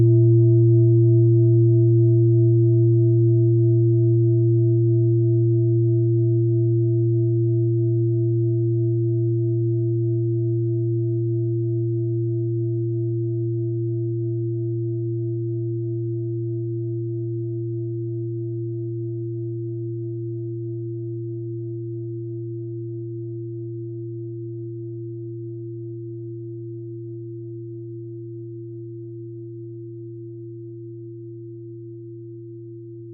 Klangschalen-Typ: Bengalen und Tibet
Klangschale 3 im Set 4
Durchmesser = 26,4cm
(Aufgenommen mit dem Filzklöppel/Gummischlegel)
klangschale-set-4-3.wav